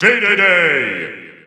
The announcer saying King Dedede's name in Japanese and Chinese releases of Super Smash Bros. 4 and Super Smash Bros. Ultimate.
King_Dedede_Japanese_Announcer_SSB4-SSBU.wav